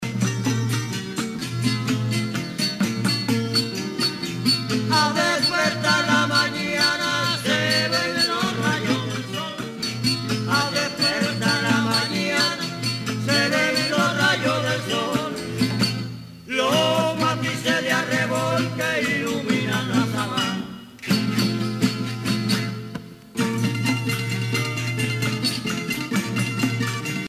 Punto corrido
Pièces musicales tirées de la Parranda Tipica Espirituana, Sancti Spiritus, Cuba
Pièce musicale inédite